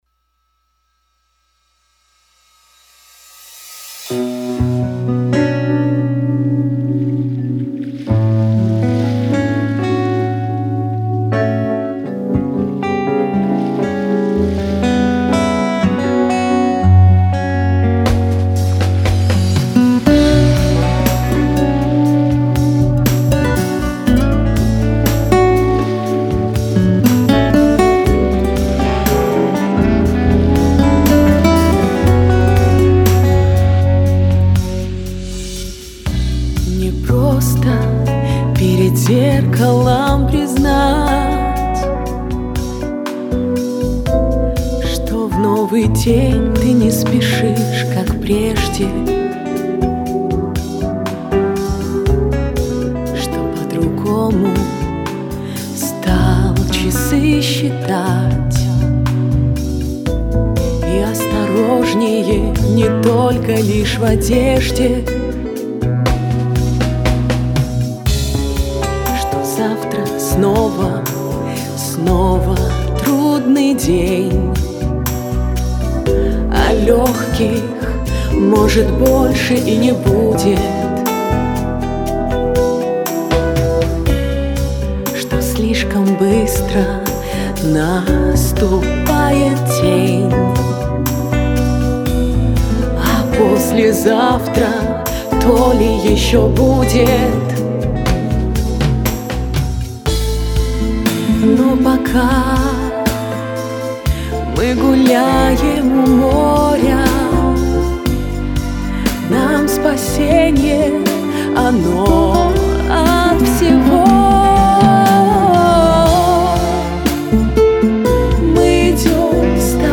а для экспериментов существует множество других стилей... всё ж для романса важен сам аккомпанемент, усиливающий смысл и эмоциональный посыл песни...